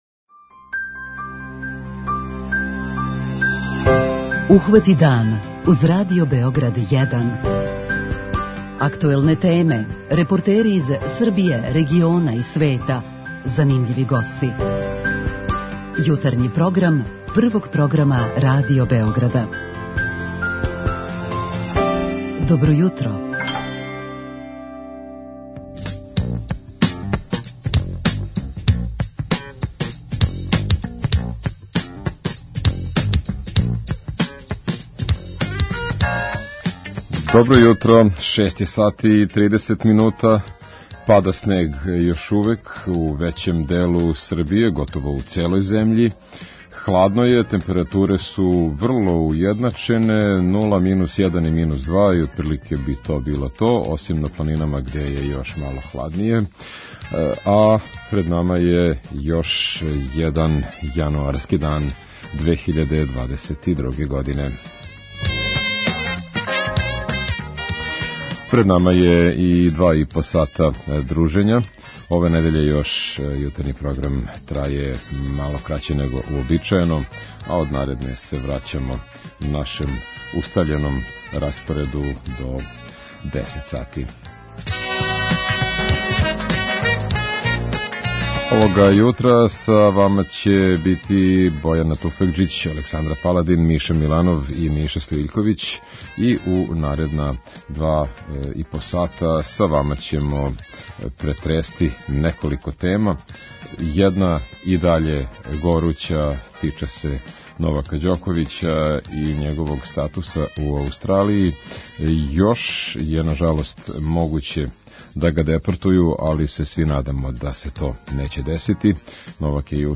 Исто питање поставићемо и нашим слушаоцима у редовној рубрици 'Питање јутра'.
Исто питање поставићемо и нашим слушаоцима у редовној рубрици "Питање јутра". преузми : 26.94 MB Ухвати дан Autor: Група аутора Јутарњи програм Радио Београда 1!